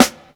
• Original Snare Drum Sample B Key 120.wav
Royality free steel snare drum sound tuned to the B note. Loudest frequency: 2149Hz
original-snare-drum-sample-b-key-120-bxa.wav